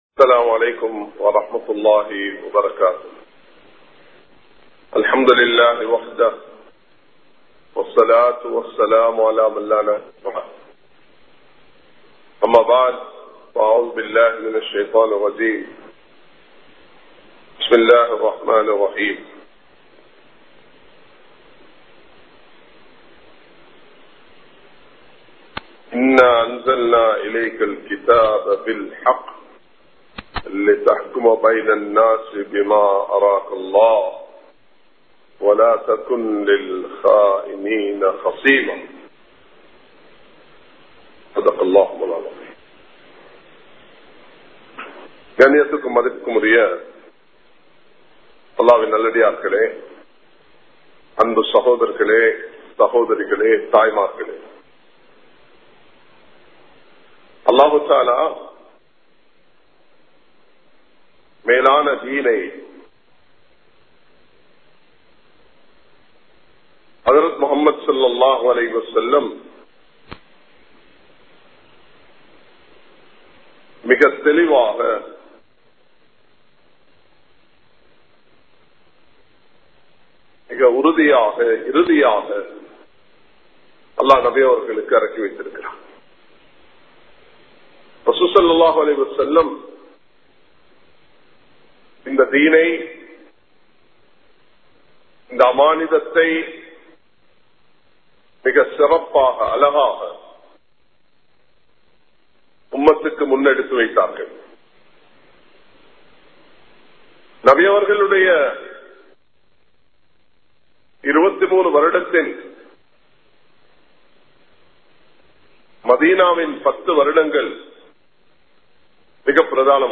எம் நாட்டை நேசியுங்கள் | Audio Bayans | All Ceylon Muslim Youth Community | Addalaichenai
Colombo 03, Kollupitty Jumua Masjith